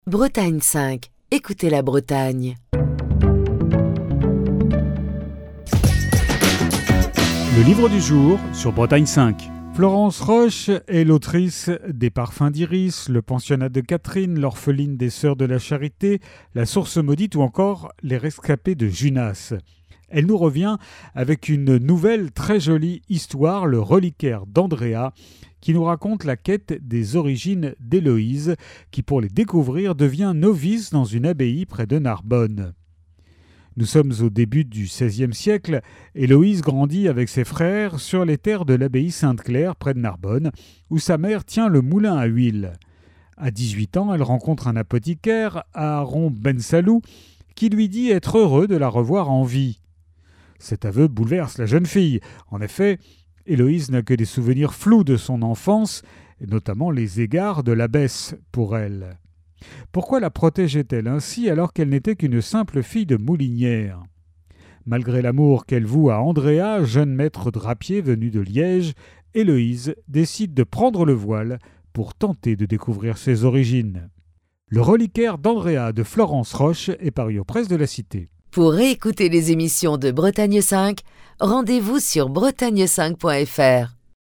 Chronique du 13 novembre 2024.